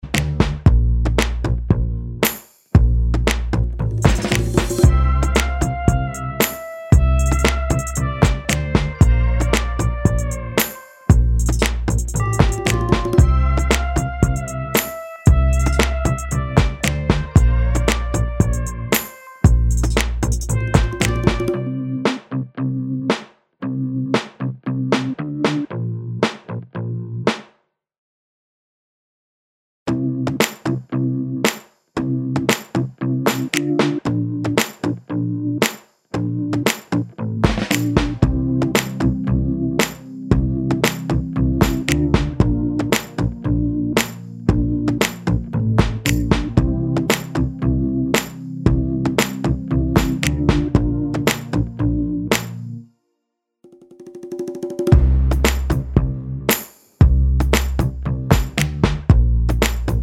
no Backing Vocals Pop (2020s) 3:27 Buy £1.50